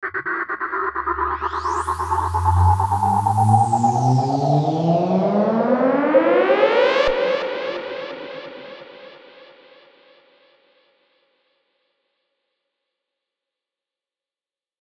Download Risers sound effect for free.
Risers